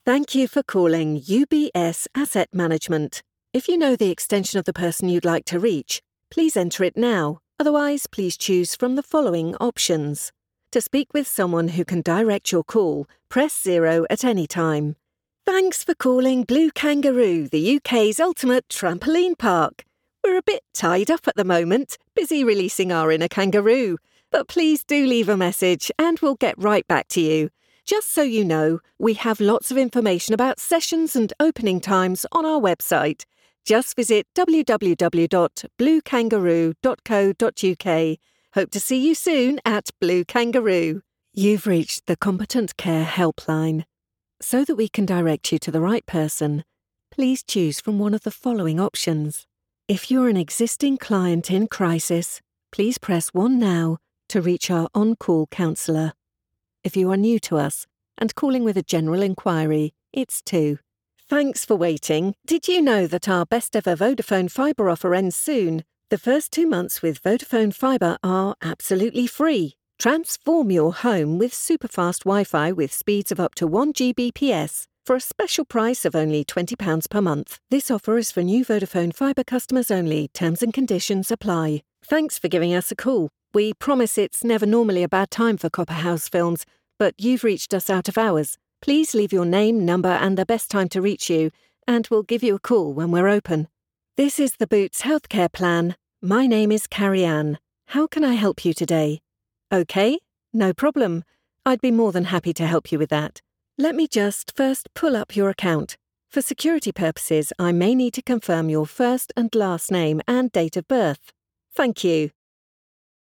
Anglais (Britannique)
Mature, Commerciale, Polyvalente, Chaude, Corporative
Téléphonie